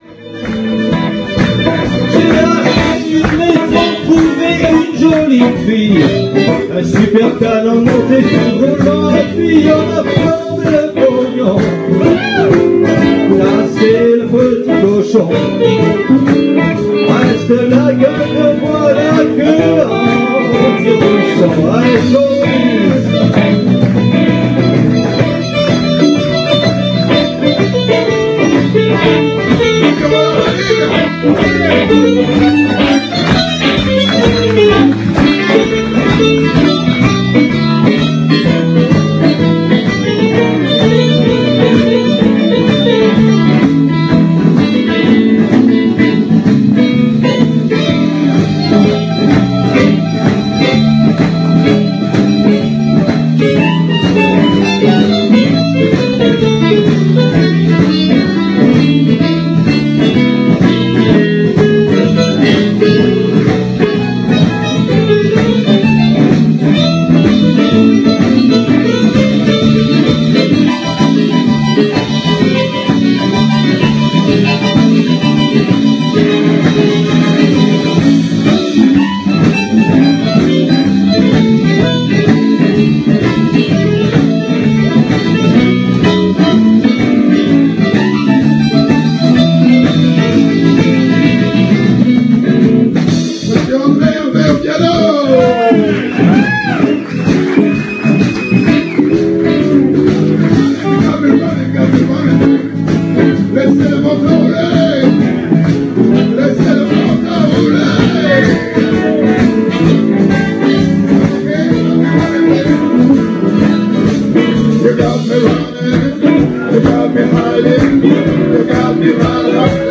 Dimanche 19/11 (après le Tremplin)